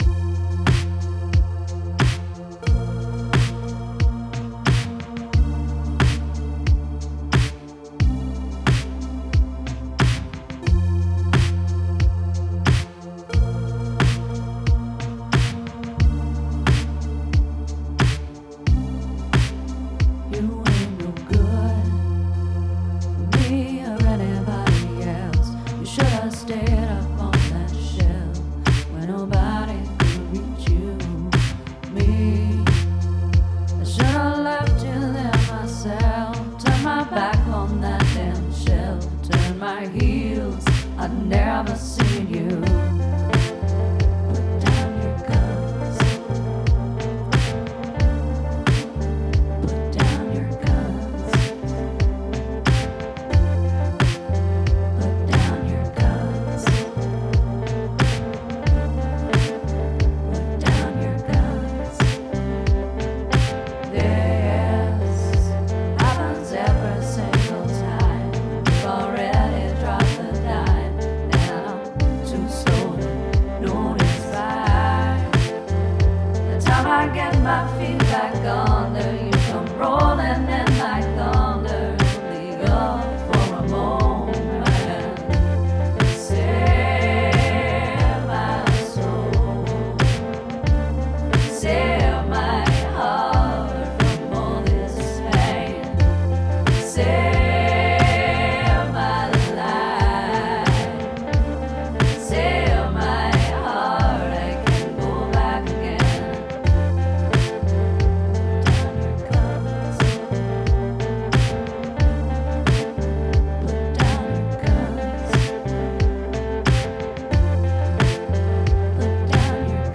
at The Park Studio in Dillon, MT